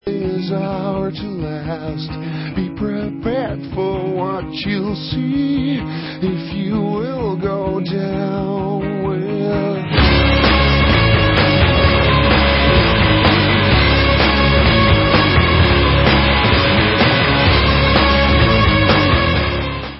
Allstar dutch rockband w/ex-anouk & kane members